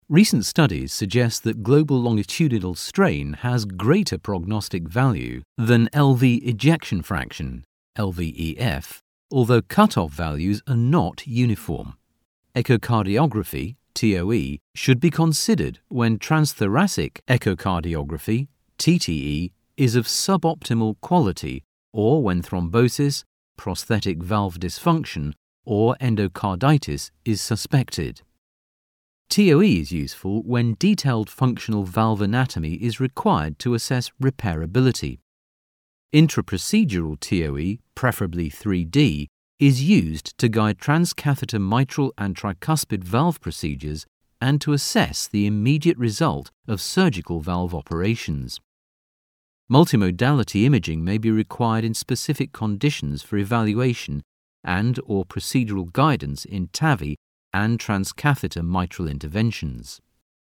Male
Current, versatile, engaging, rich, warm.
Medical Narrations
Very Technical
Words that describe my voice are Conversational, Natural, Versatile.